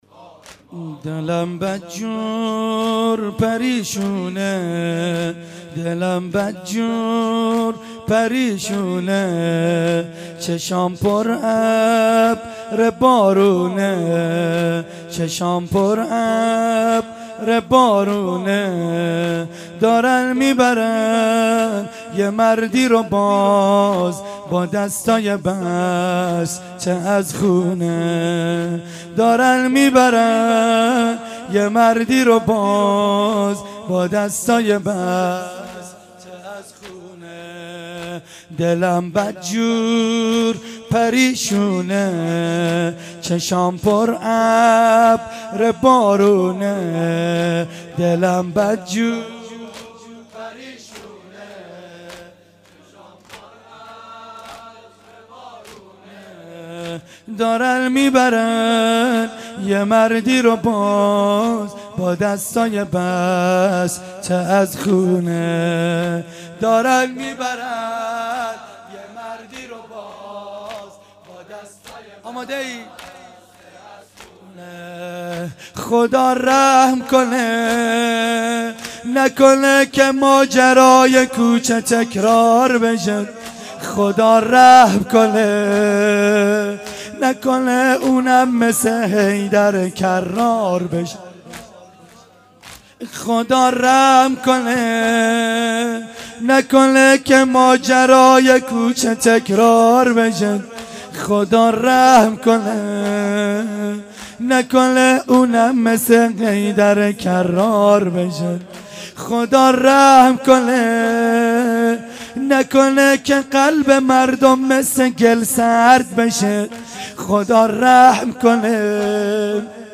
:: گزارش صوتی برنامه شهادت امام صادق علیه السلام 1437 هـ.ق - 1395 هـ.ش ::